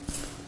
杂项音效 " 拿起钥匙2
描述：用Rode NT1A录制，从表面拾取一堆键的声音。
标签： 按键 钥匙圈 皮卡
声道立体声